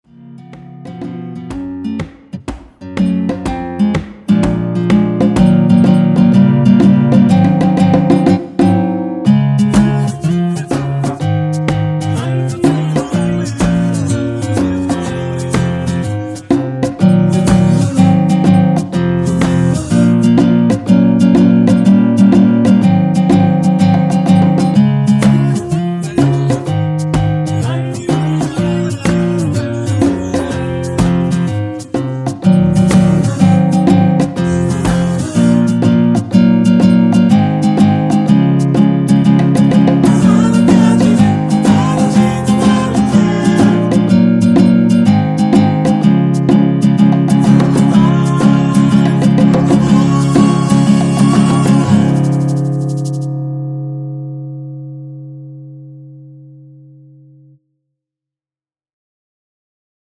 전주 없이 시작 하는곡이라 노래 하시기 편하게 전주 2마디 많들어 놓았습니다.(일반 MR 미리듣기 확인)
원키에서(-3)내린 코러스 포함된 MR입니다.
Db
앞부분30초, 뒷부분30초씩 편집해서 올려 드리고 있습니다.